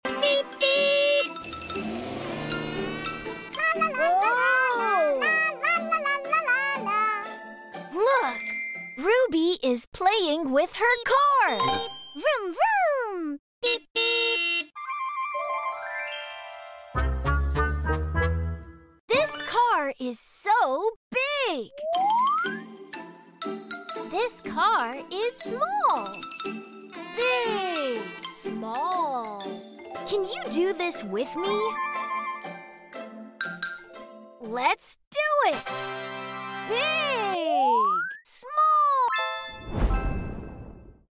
🎈✨ With catchy jingles and hands-on play, little ones will laugh, learn, and dance along with Ruby and her friends.